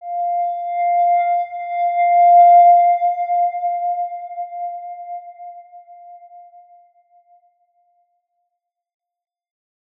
X_Windwistle-F4-pp.wav